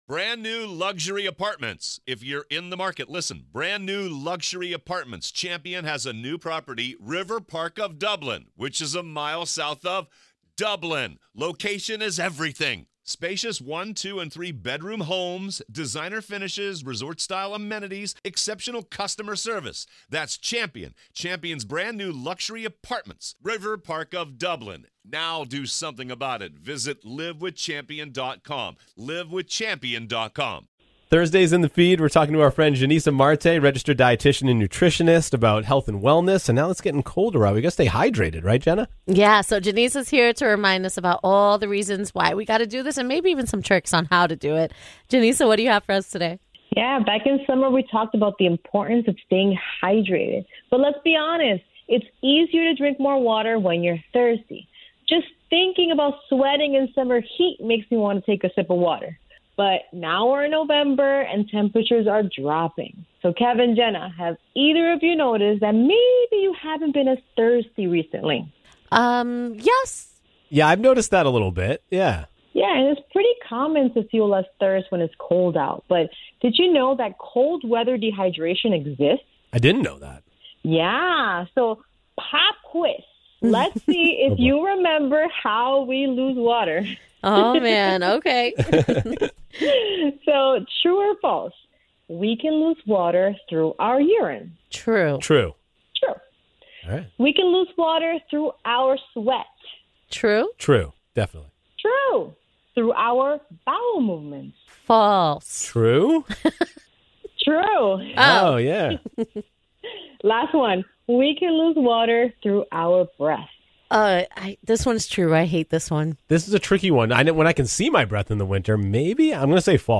chat with an expert about all things health and wellness.